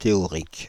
Ääntäminen
Ääntäminen France (Île-de-France): IPA: /te.ɔ.ʁik/ Paris: IPA: [te.ɔ.ʁik] Haettu sana löytyi näillä lähdekielillä: ranska Käännös Ääninäyte Adjektiivit 1. theoretical 2. academic US Suku: f .